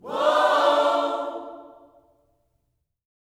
WHOA-OHS 7.wav